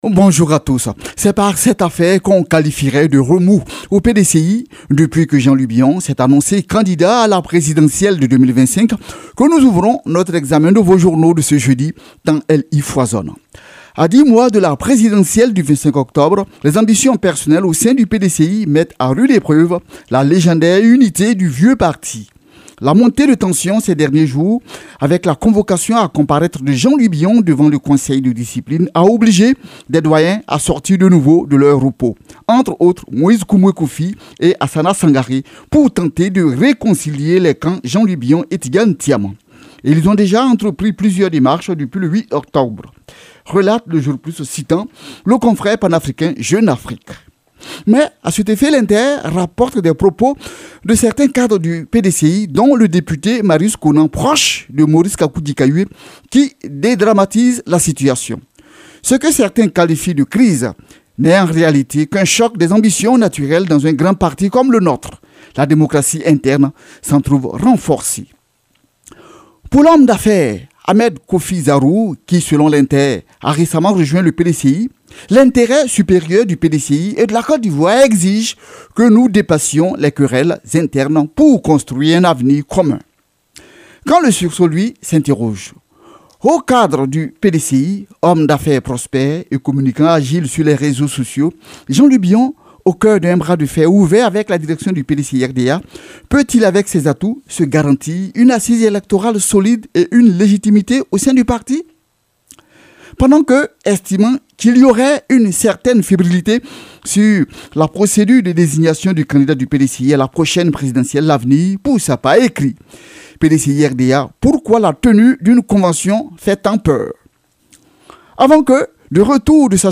Revue de Presse du 5 décembre 2024 - Site Officiel de Radio de la Paix
revue-de-presse-du-5-decembre-2024.mp3